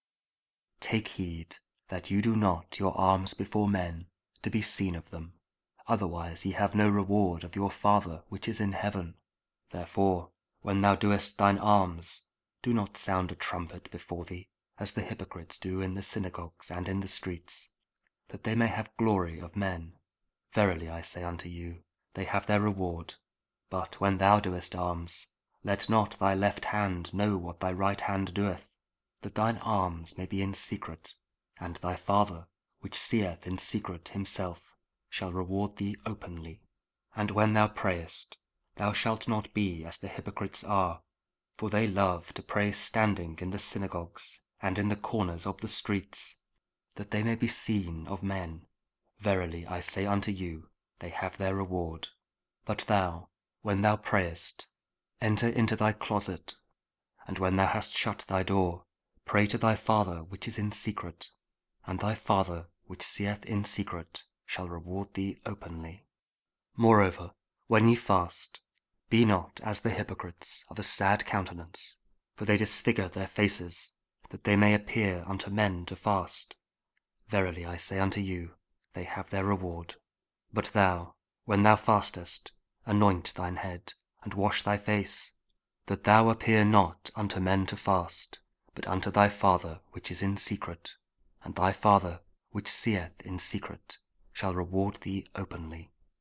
Matthew 6: 1-6, 16-18 – Ash Wednesday & Wednesday of Ordinary Time week 11 (King James Audio Bible, Spoken Word)